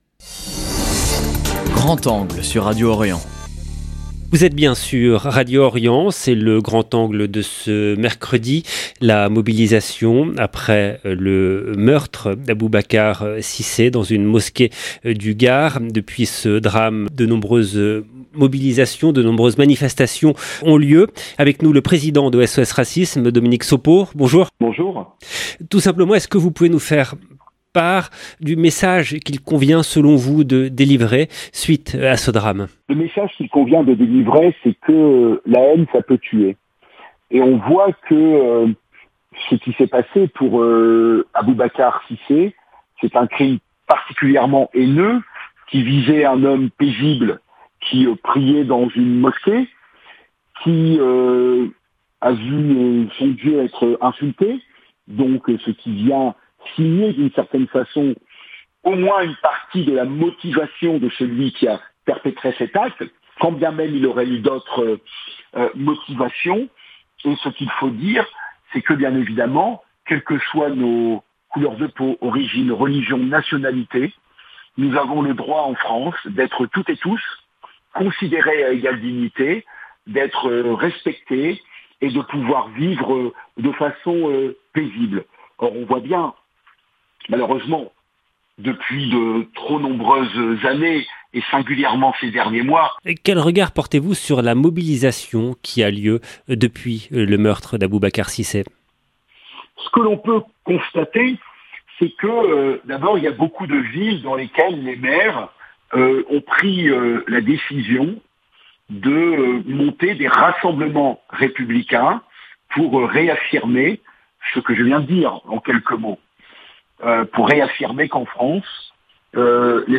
Entretien avec le président de SOS Racisme Dominique Sopo. 0:00 7 min 5 sec